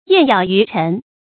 雁杳魚沉 注音： ㄧㄢˋ ㄧㄠˇ ㄧㄩˊ ㄔㄣˊ 讀音讀法： 意思解釋： 比喻音信斷絕。